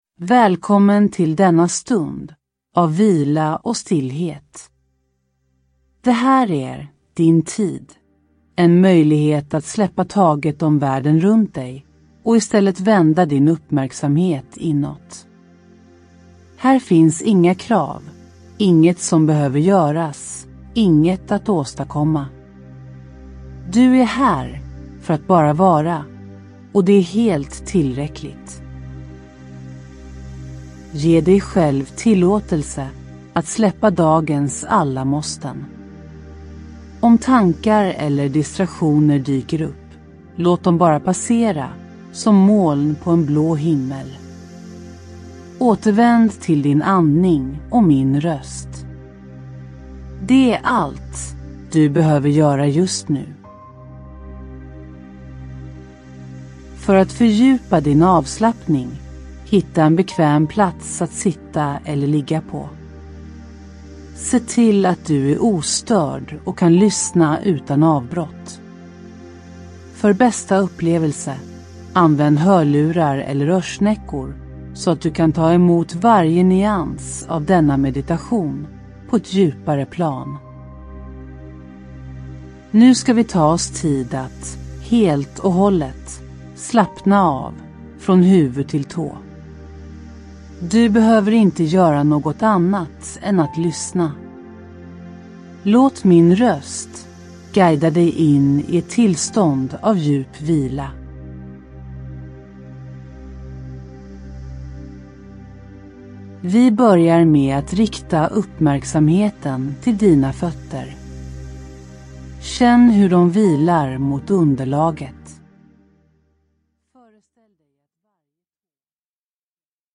Ljudbok
Med mjuka instruktioner, visualiseringar och stärkande affirmationer lär du dig att: